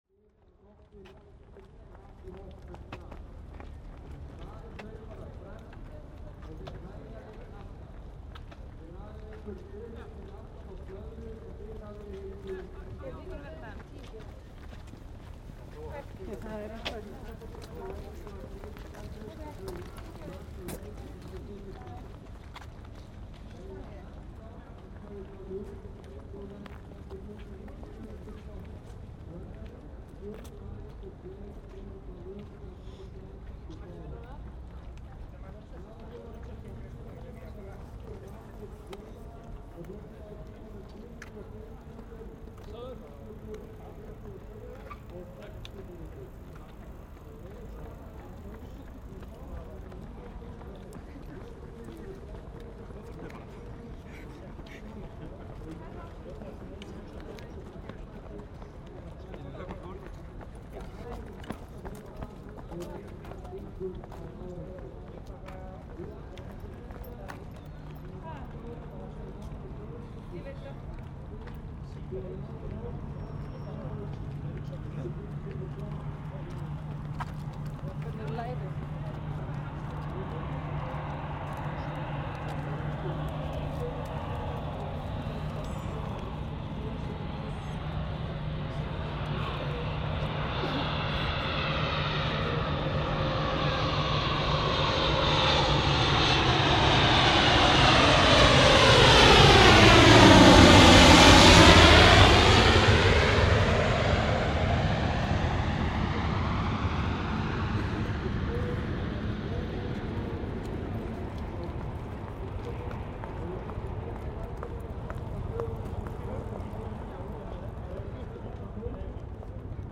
The weather was calm and no painful traffic noise as usual.
I placed my microphones in a bush about 500 meters from the starting point. In the beginning of the recording most footsteps are coming from right to left when runners are on the way to the starting point. Air plains pass by from left to right and land on Reykjavik Airport.
Few seconds after the race starts, runners begin to pass the microphones from left to right.
Thousands of footsteps in just five minutes….and another airplane passes by the same way, but on the way to the airport.
Helsti hávaðinn kom frá gjallarhorni keppnishaldara og frá tveimur flugvélum sem komu til lendingar á Reykjavíkurflugvelli.